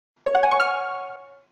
gameStartSound.93001ff1.mp3